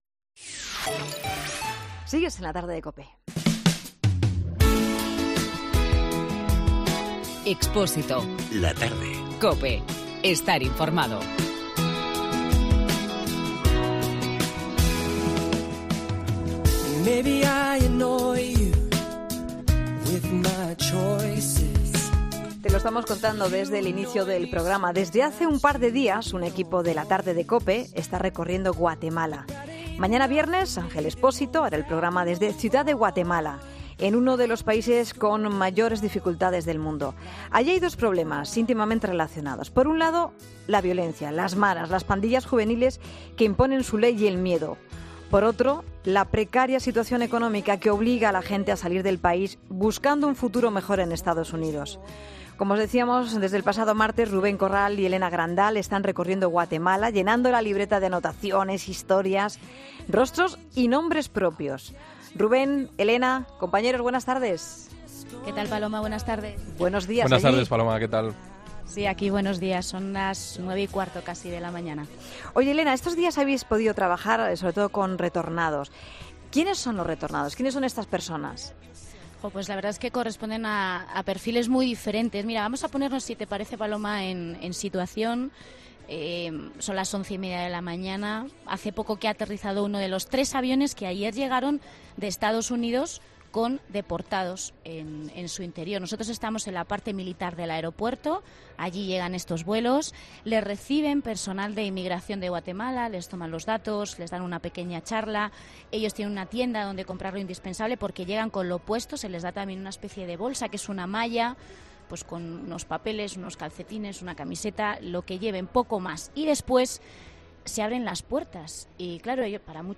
Estas pandillas que atemorizan las calles de Guatemala actúan con la extorsión, el chantaje y la violencia. ESCUCHA LA ENTREVISTA COMPLETA